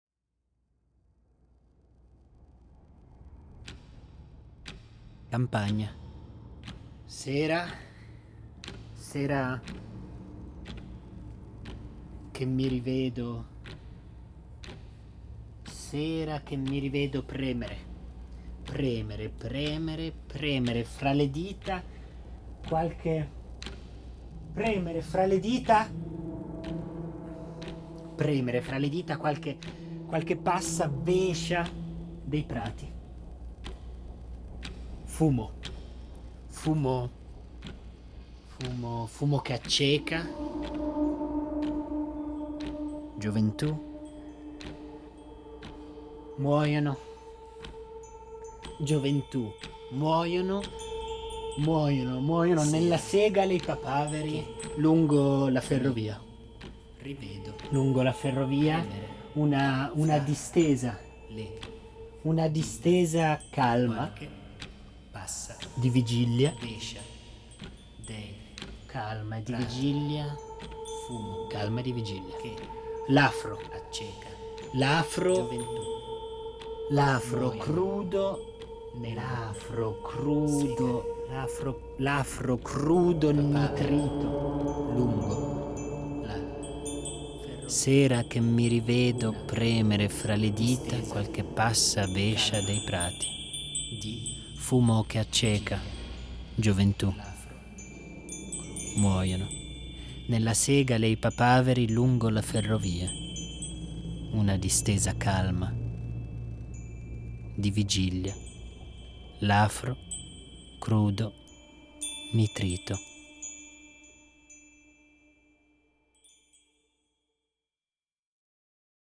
Sonorizzare delle poesie.